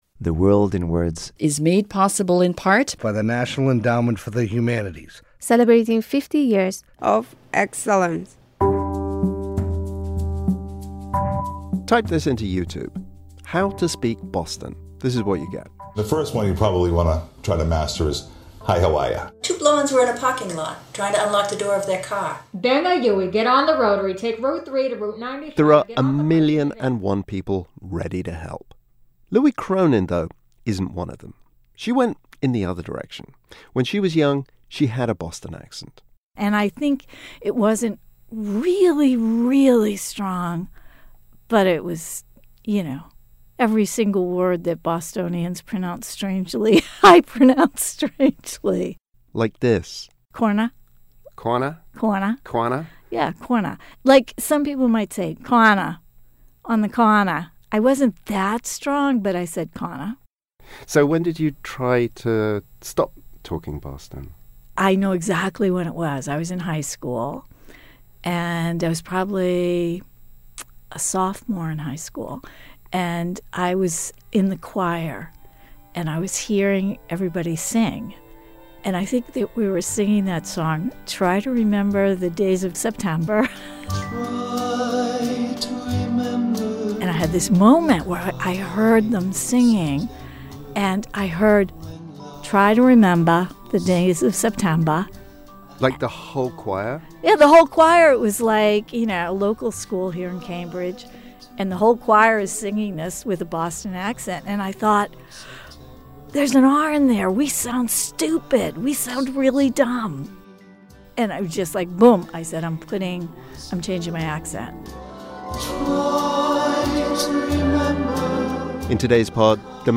So whether you're from Thailand or Tennessee, you may want to get rid of your accent. We hear from a few such people, and from someone who has no interest in changing his accent.